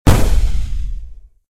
cannon-fire.ogg